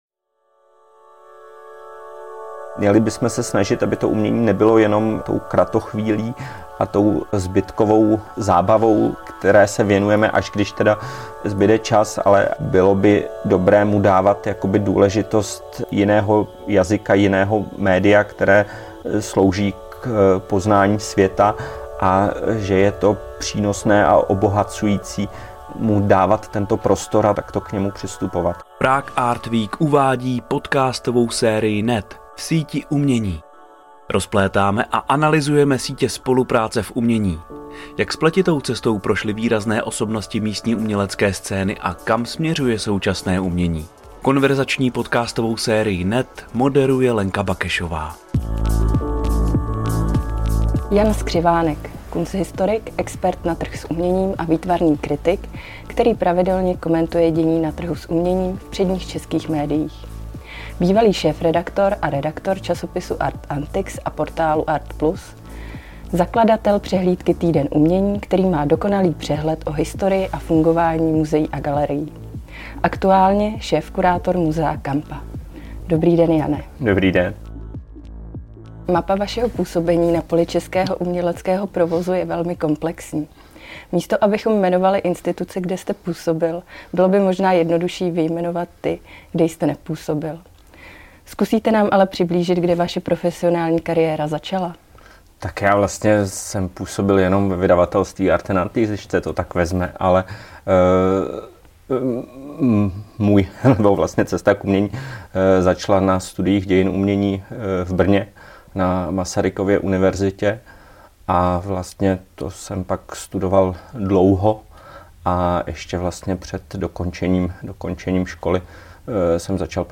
A jaké aspekty ovlivňují hodnotu uměleckého díla? Toto a mnohem více se dozvíte v rozhovoru. V konverzační podcastové sérii NET rozplétáme a analyzujeme sítě spolupráce v umění.